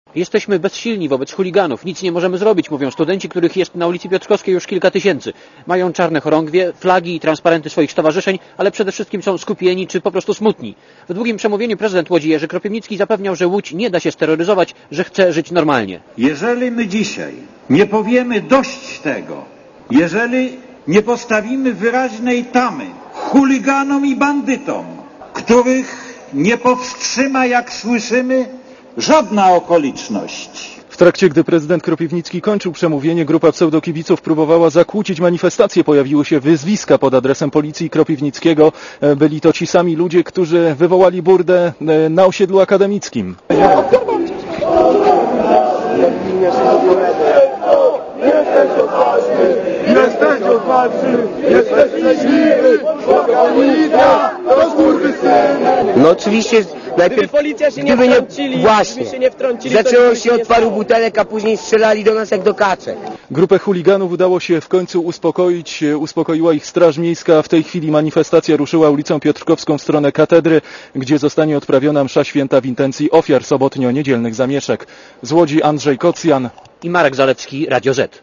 Relacja reporterów Radia ZET